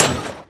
sounds / mob / blaze / hit3.mp3
hit3.mp3